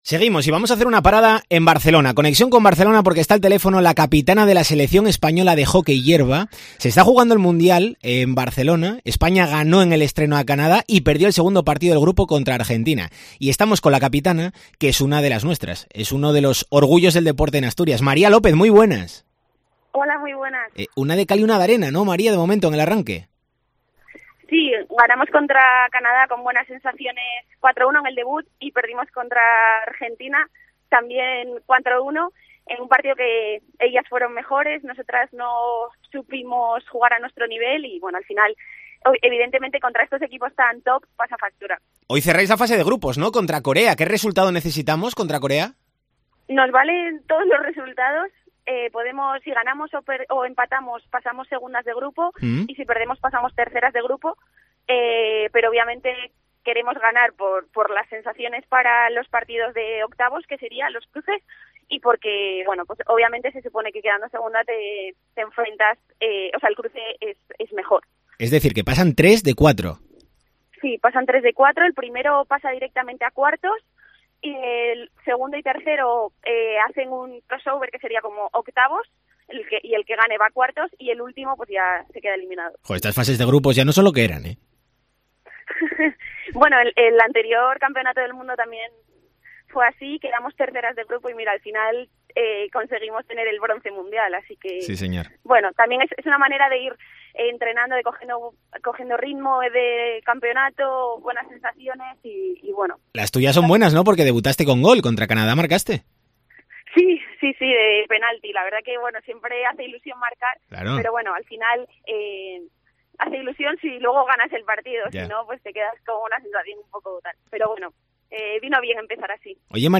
ENTREVISTA DCA